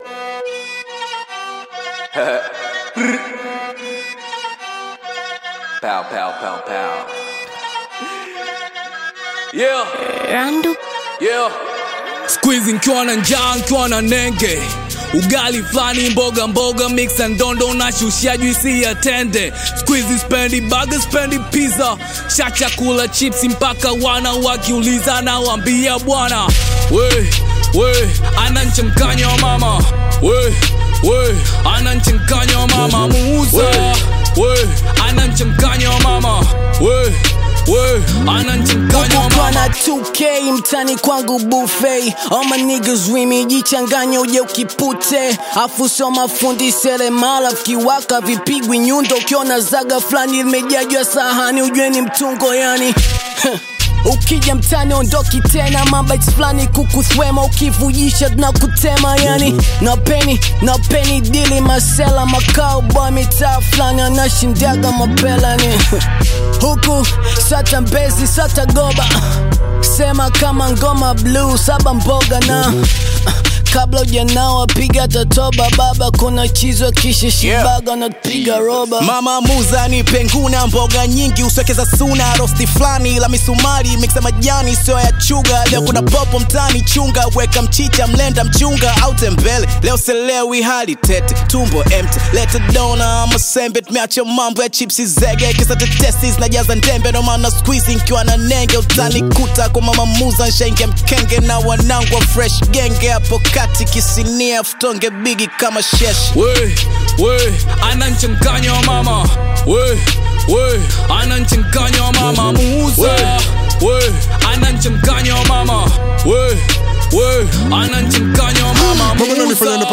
lively Tanzanian Bongo Flava/Hip-Hop single